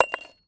metal_small_movement_10.wav